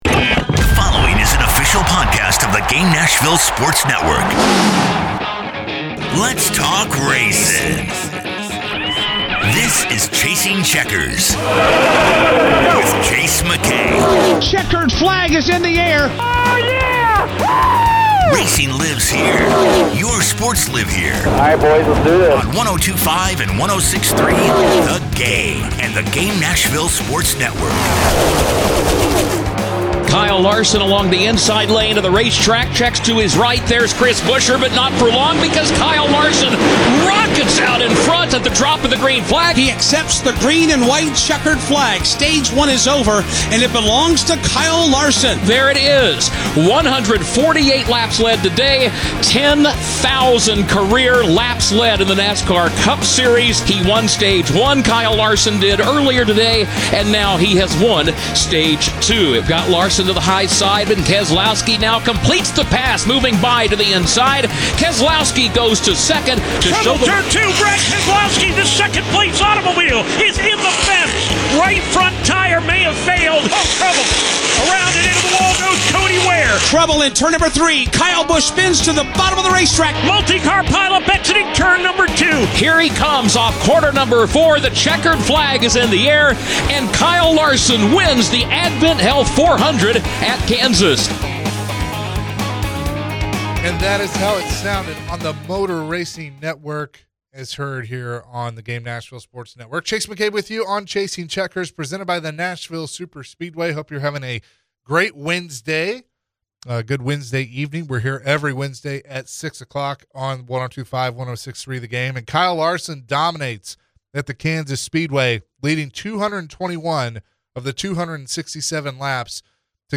You'll also hear a conversation with driver, Bubba Wallace.